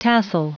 Prononciation du mot tassel en anglais (fichier audio)
Prononciation du mot : tassel